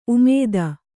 ♪ umēda